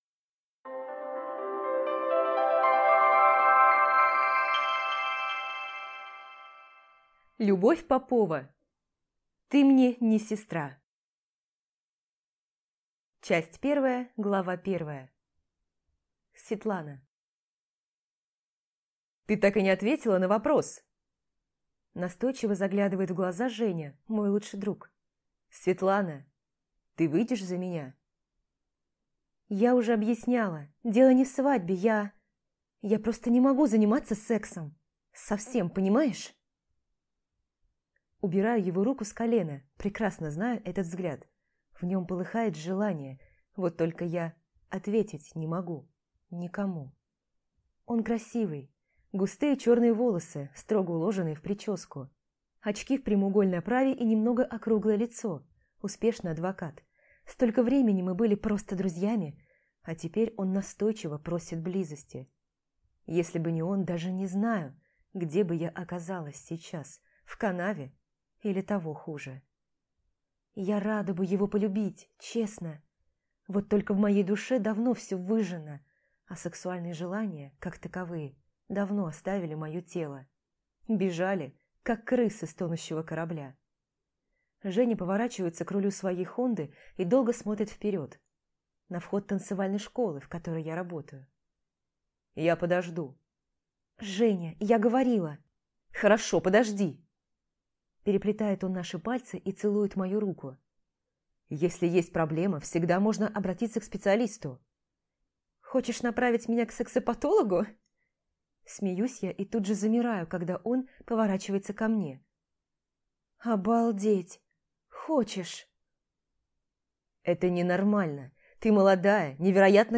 Аудиокнига Ты мне не сестра | Библиотека аудиокниг